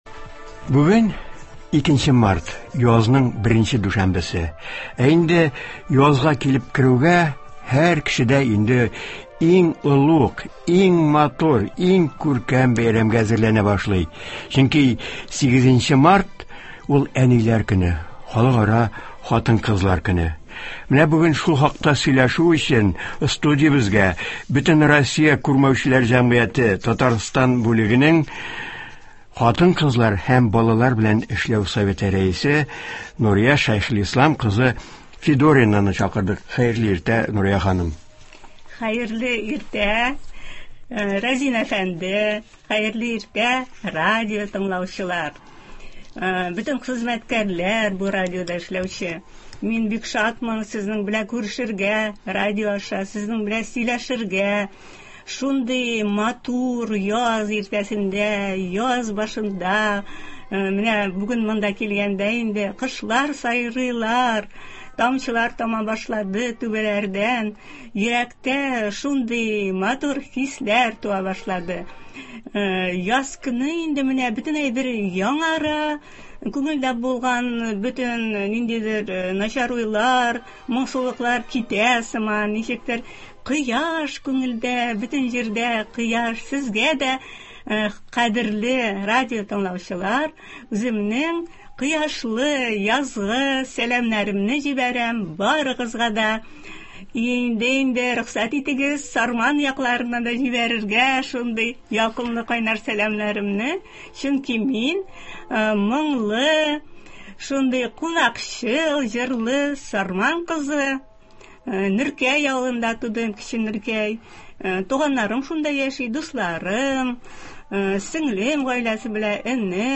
турыдан-туры эфирда совет эшчәнлеге турында сөйләячәк һәм тыңлаучылар сорауларына җавап бирәчәк.